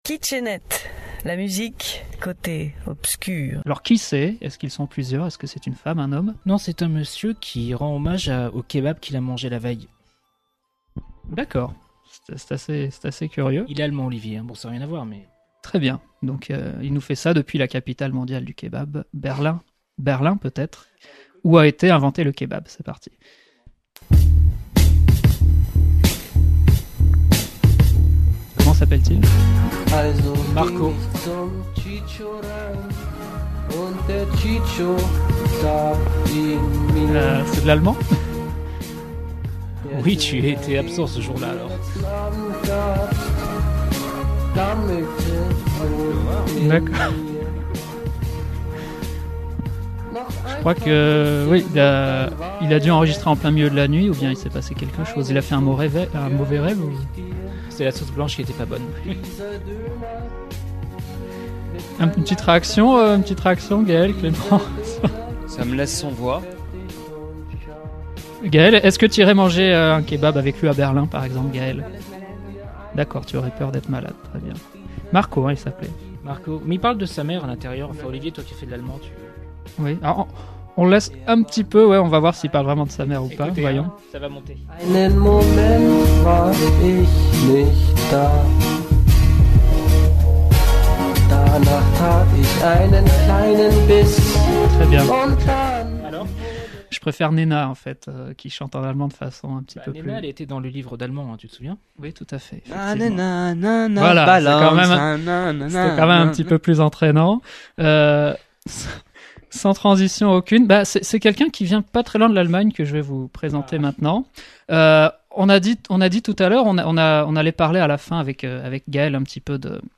Chaque animateur de « Kitsch et Net » fait découvrir en live à ses petits camarades des extraits musicaux dits « HARDkitschs » (voir ici la définition), qui ne seront pas diffusés en entier…
Savourez à volonté tous ces happenings pleins de surprises, de délires et surtout de gros éclats de rire, et retrouvez aussi des informations sur les chanteurs diffusés…
Attention à vos oreilles…